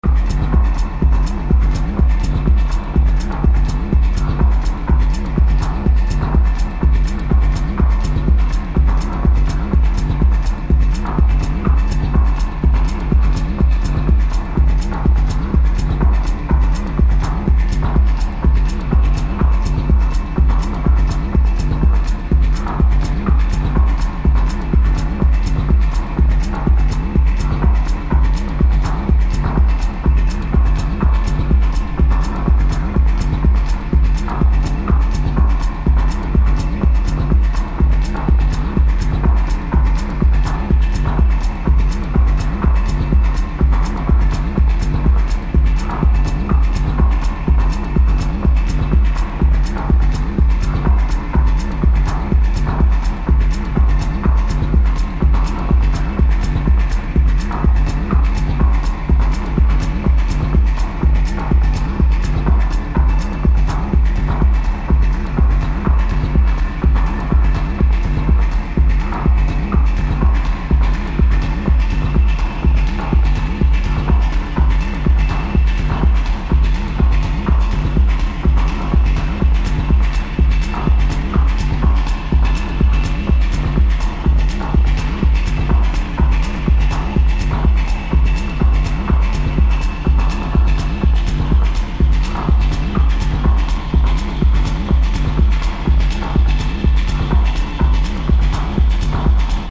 deep trippy dark techno
Techno